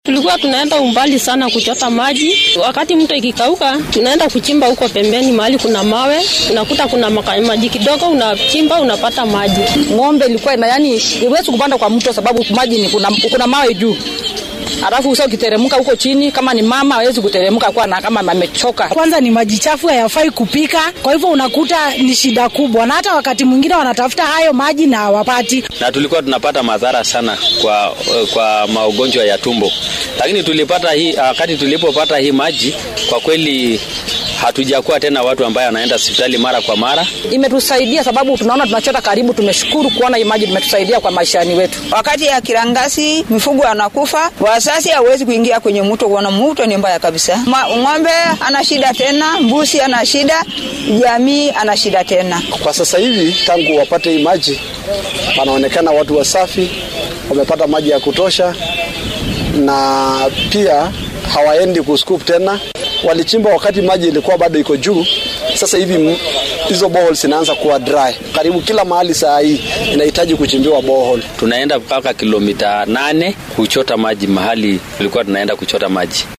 Waxaa la filayaa inuu yareeyo walaaca dadka deegaanka oo masaafo dheer lugeyn jiray si ay biyo u helaan. Dadka deegaanka oo mashruucan biyaha ka mahadceliyay ayaa sidatan dareenkooda la wadaagay warbaahinta